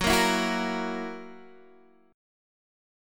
Listen to F#7 strummed